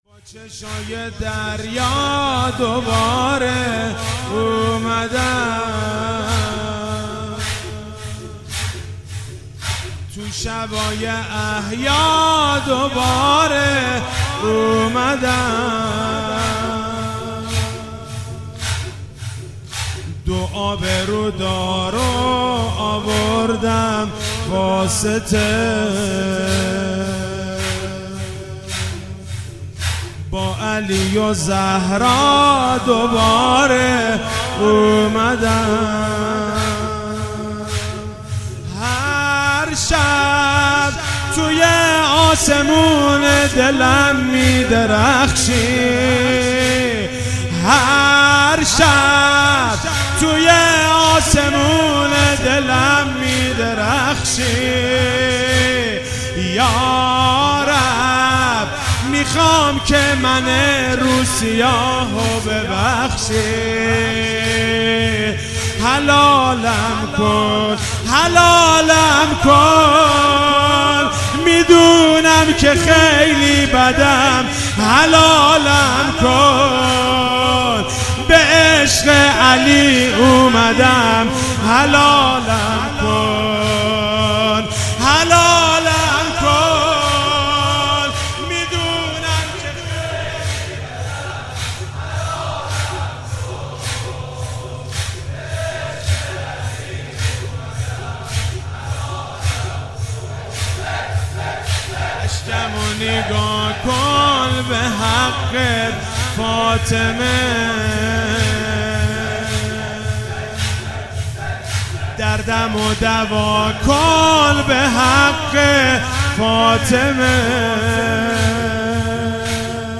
شب 23 ماه مبارک رمضان 96(قدر) - زمینه - با چشای دریا دوباره اومدم
مداحی